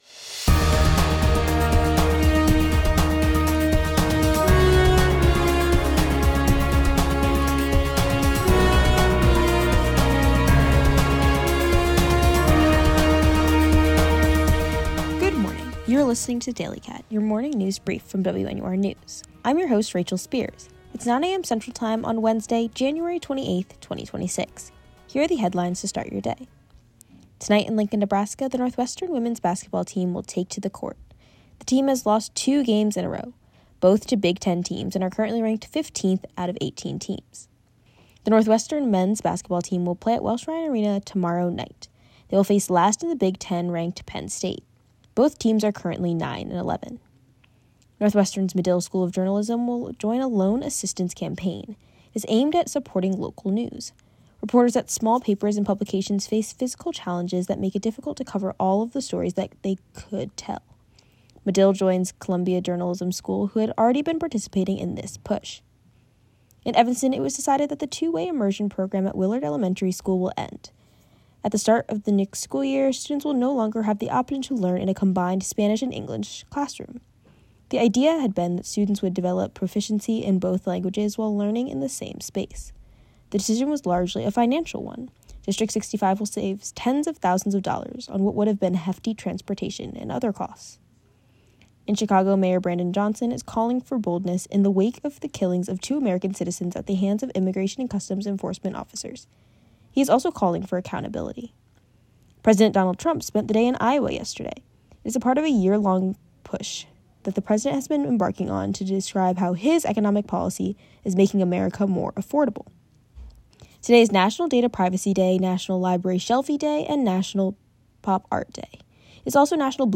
Wednesday January 28, 2026: Northwestern basketball, Willard Elementary School, Brandon Johnson, Donald Trump. WNUR News broadcasts live at 6 pm CST on Mondays, Wednesdays, and Fridays on WNUR 89.3 FM.